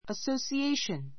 əsouʃiéiʃən アソウシ エ イション